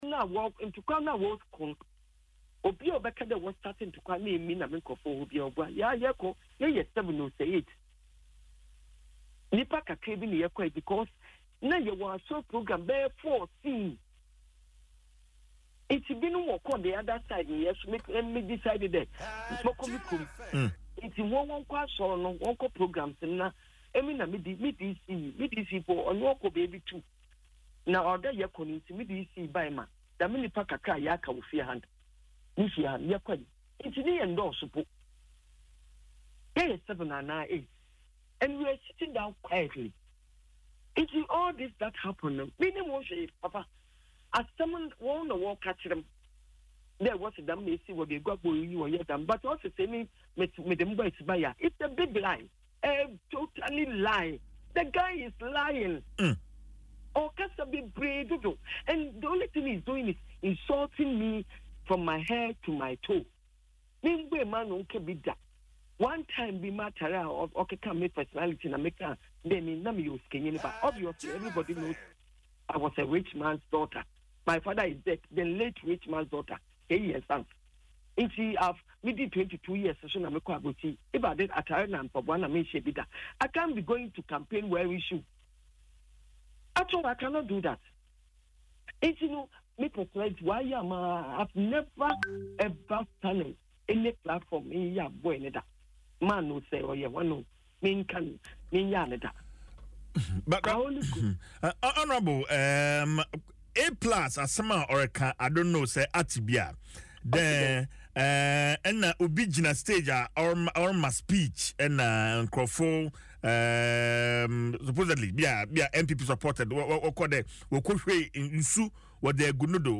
In an interview on Adom FM’s Dwaso Nsem, the MP acknowledged that she was present at the event but was accompanied by only eight people, including the District Chief Executive (DCE), Benjamin Kojo Otoo.
Listen to the MP in the audio above: